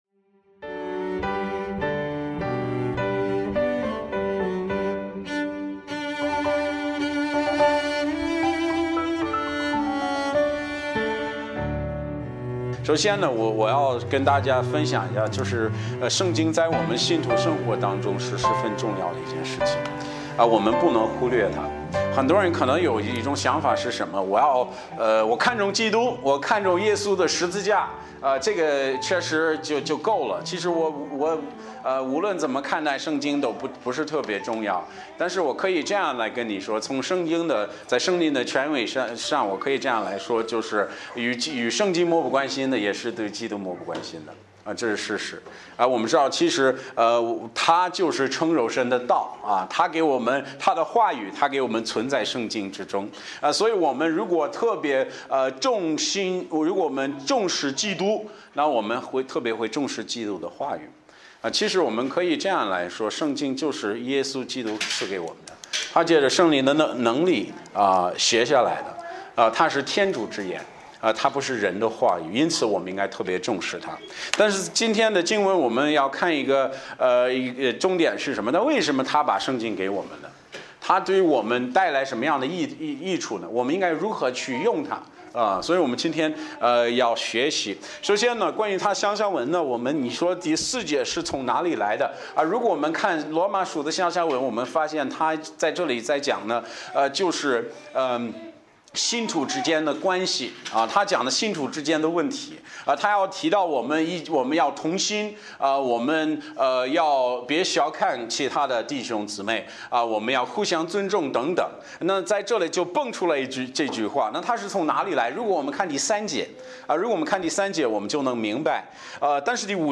Bible Text: 罗玛书 15：4 | 讲道者